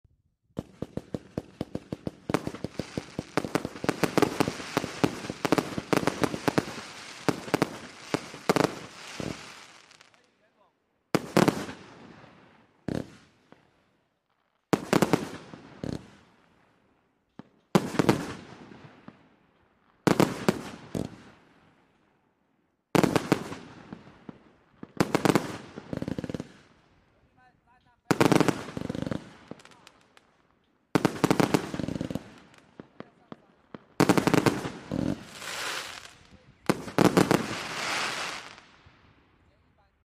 Red spider effect fireworks show sound effects free download